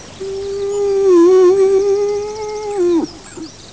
Sie stößt nasale, stöhnende Lockrufe aus (
Kuh), die vom Bullen mit einem hustenden Bellen (
kuh.wav